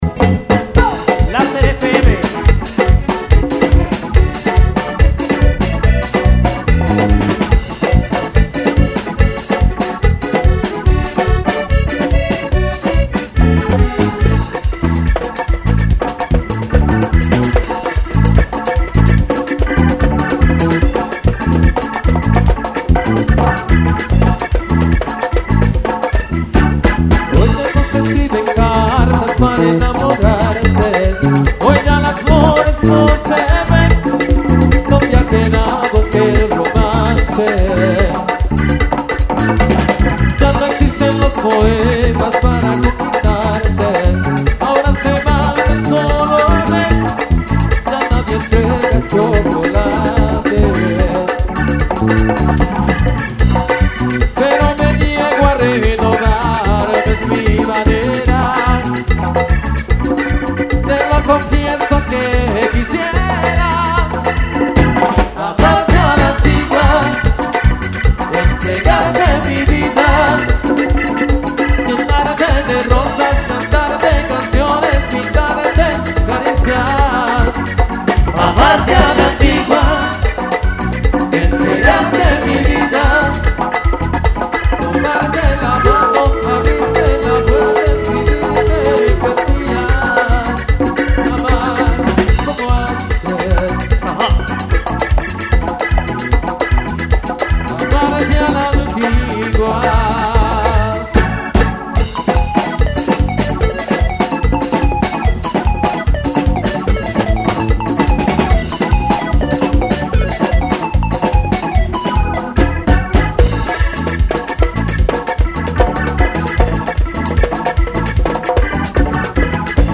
Vivo Feria S.p.s 2014